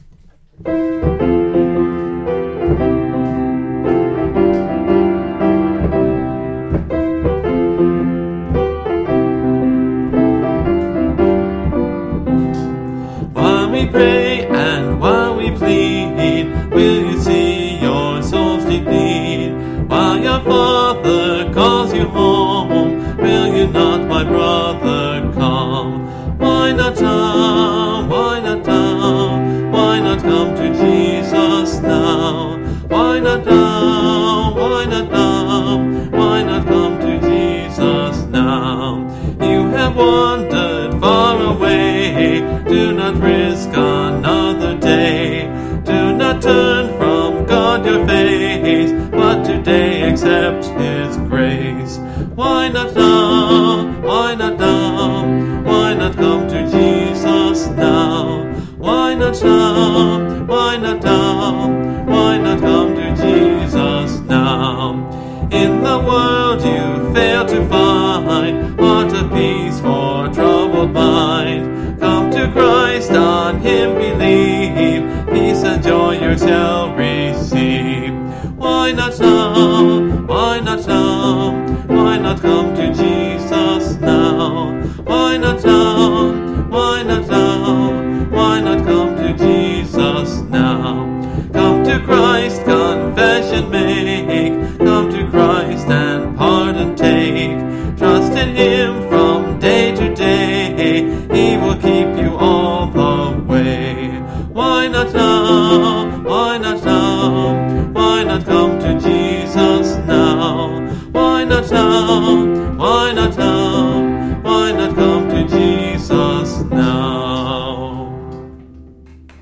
(Part of a series singing through the hymnbook I grew up with: Great Hymns of the Faith)